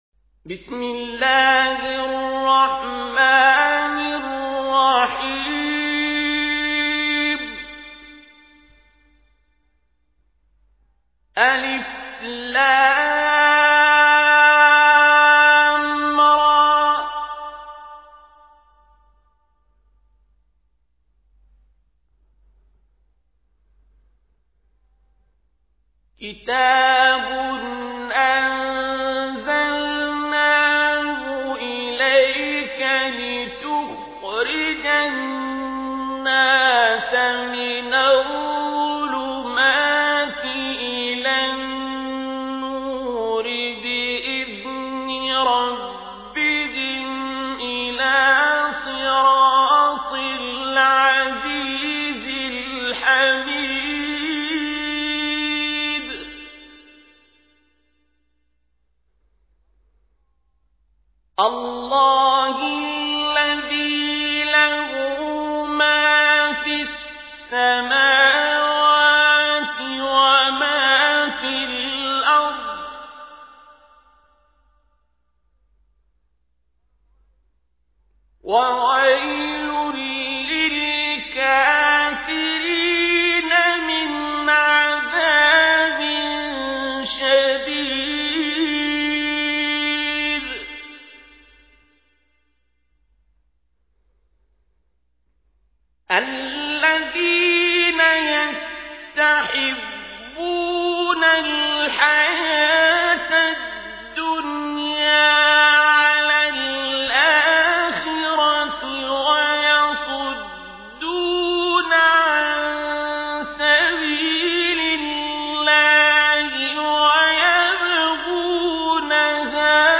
تحميل : 14. سورة إبراهيم / القارئ عبد الباسط عبد الصمد / القرآن الكريم / موقع يا حسين